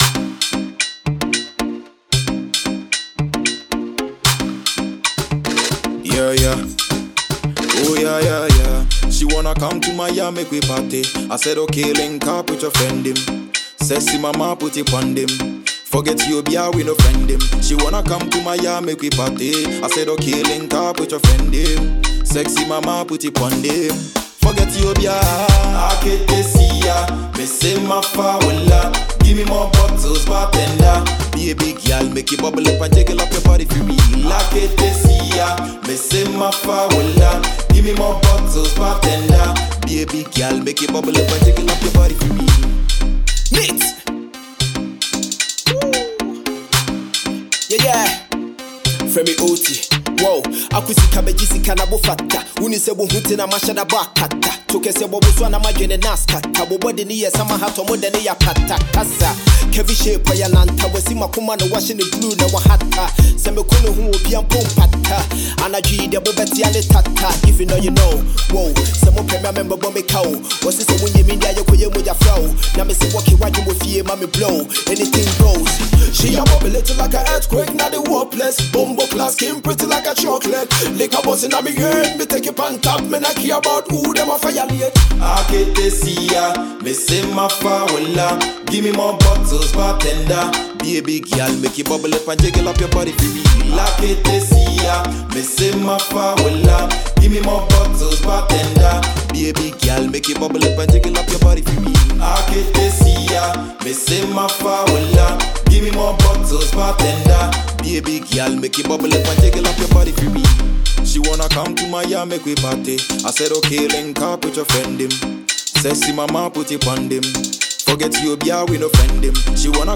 With its bouncy rhythm, sweet melodies
Afrobeat